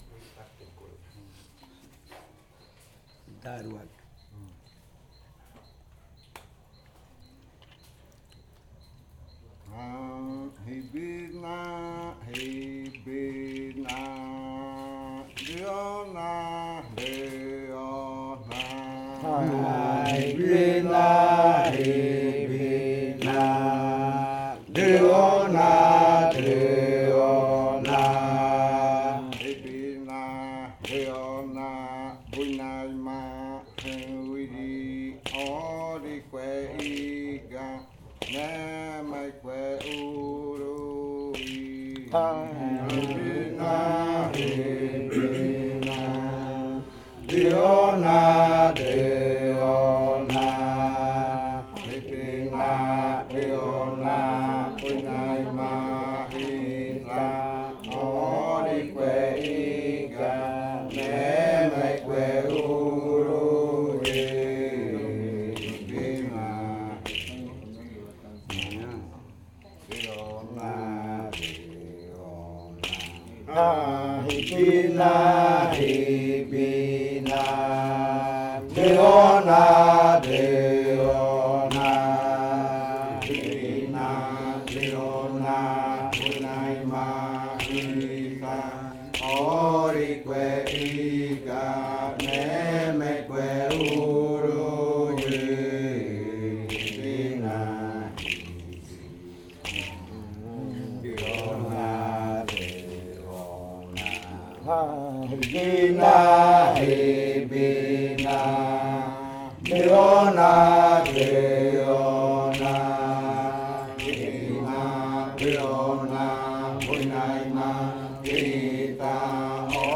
Canto de la variante muinakɨ
Leticia, Amazonas
con el grupo de cantores sentado en Nokaido. Este canto hace parte de la colección de cantos del ritual yuakɨ murui-muina (ritual de frutas) del pueblo murui, llevada a cabo por el Grupo de Danza Kaɨ Komuiya Uai con apoyo de un proyecto de extensión solidaria de la UNAL, sede Amazonia.
with the group of singers seated in Nokaido.